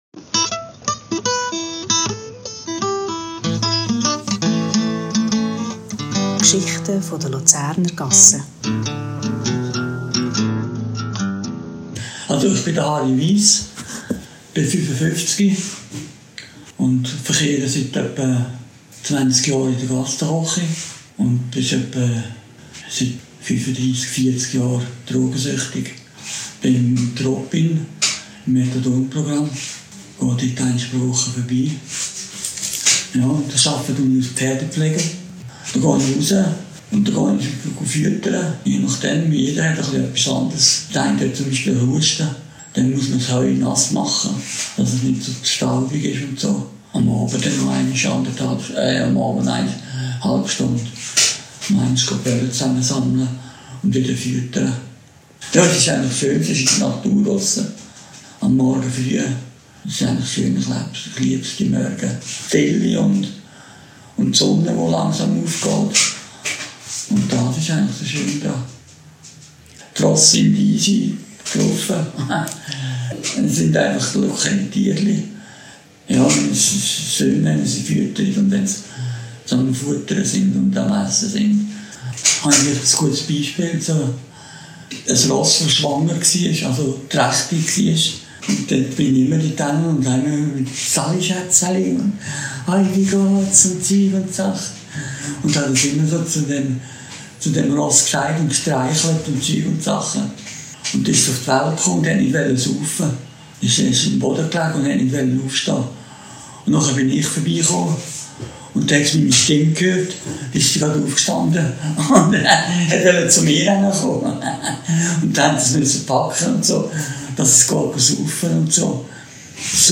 / aufgenommen am 25. August 2025 bei Tragwerk BeratungPLUS.